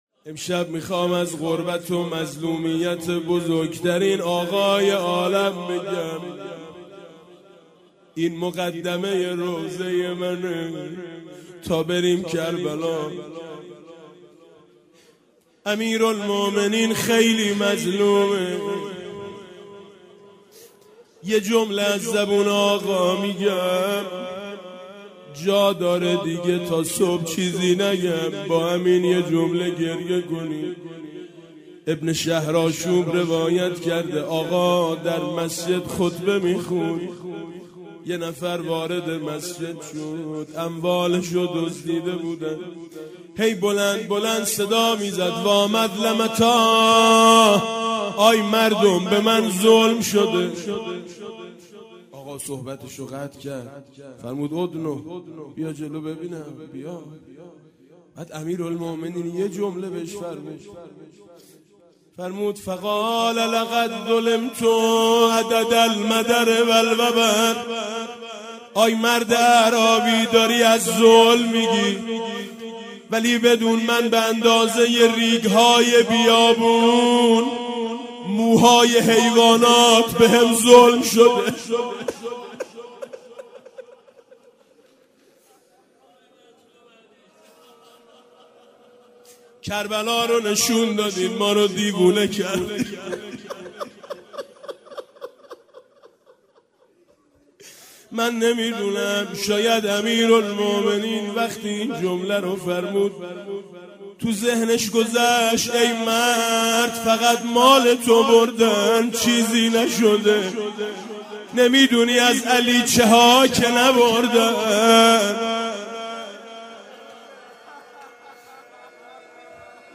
همزمان با فرارسیدن ایام شهادت حضرت امام علی علیه السلام فایل صوتی گلچین مداحی با نوای مداحان اهل بیت (ع) را می شنوید.